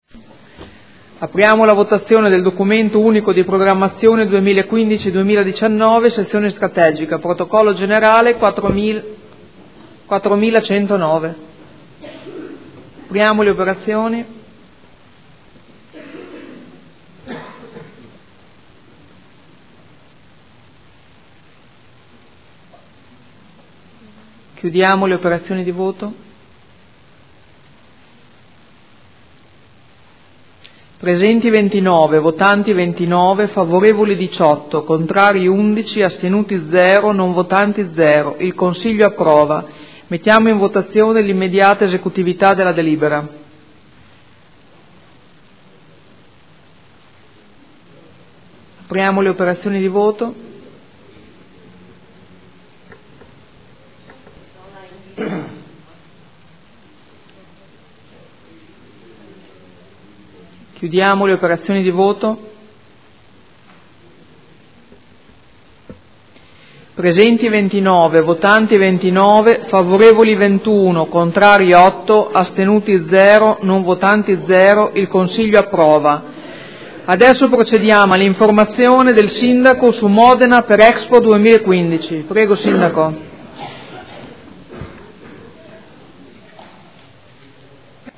Seduta del 29/01/2015. Documento Unico di Programmazione 2015/2019 – Sezione strategica.